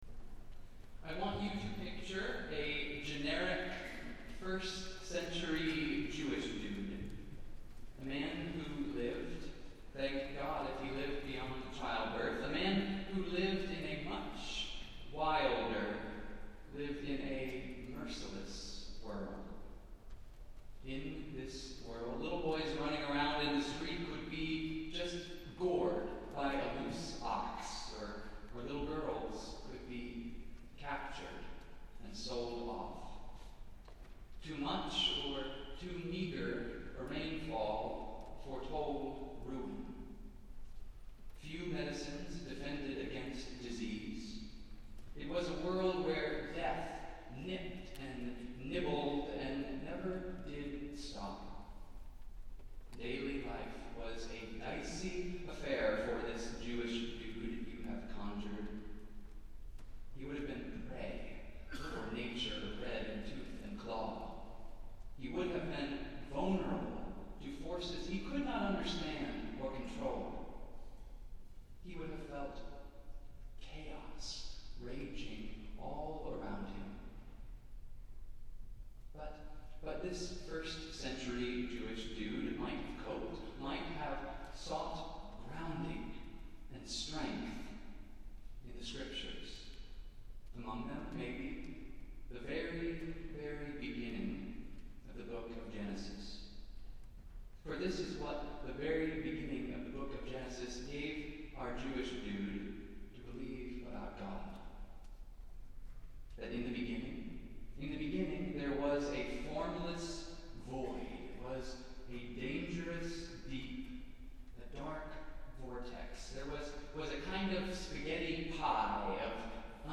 Festival Worship - First Sunday after Epiphany